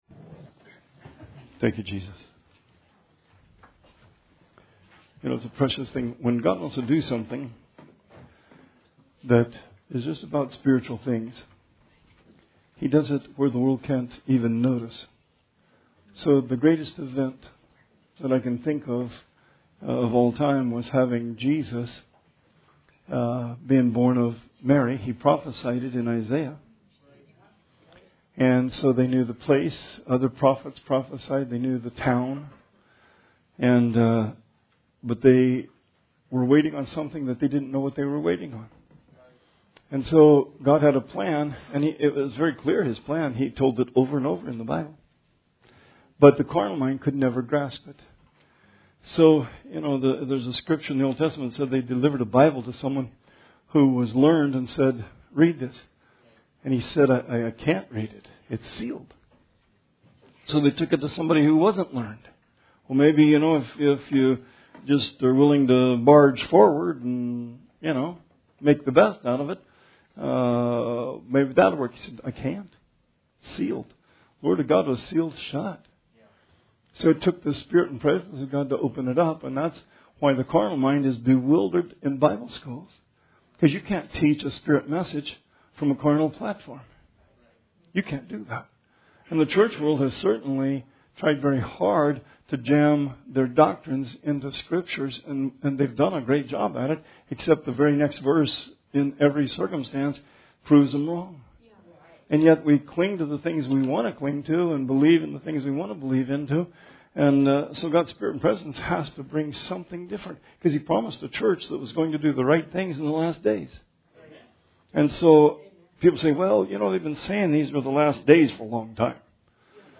Sermon 8/9/20